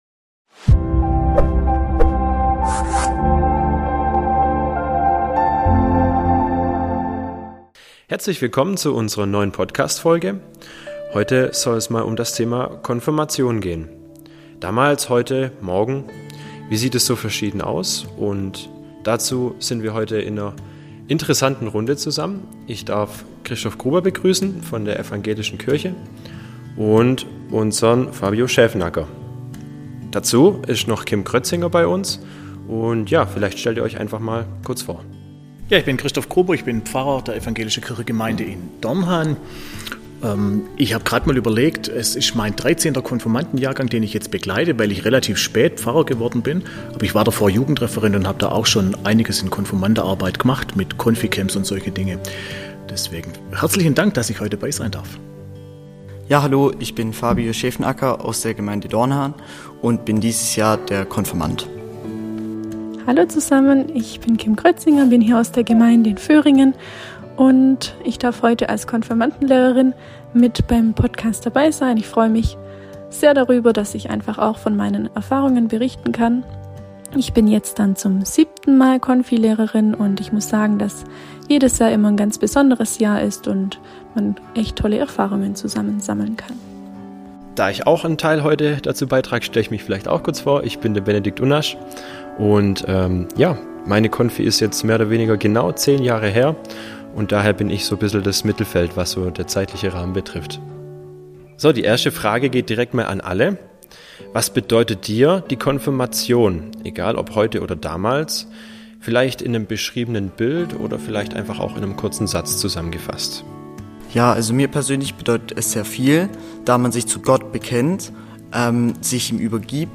Beschreibung vor 11 Monaten Was bedeutet die Konfirmation eigentlich - für einen Konfirmanden, für jemand, der sie vor ein paar Jahren erlebt hat und für die, die die Konfirmanden auf diesem Weg begleiten? In dieser kompakten Runde sprechen wir mit Jugendlichen, einer Konfi-Lehrerin und einem Geistlichen über Erinnerungen, Erwartungen, und die Frage: Was bleibt vom großen Tag?